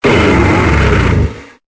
Cri de Tranchodon dans Pokémon Épée et Bouclier.